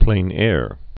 (plānâr, plĕ-nĕr)